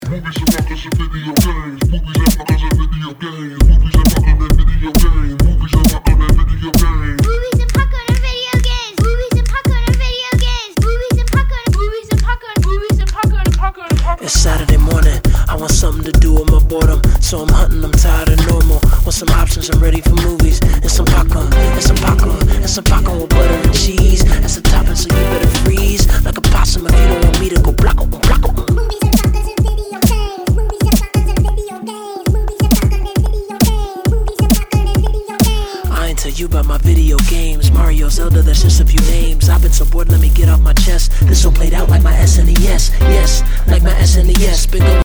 Hip Hop, soul and electronic